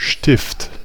The term Stift (German: [ˈʃtɪft]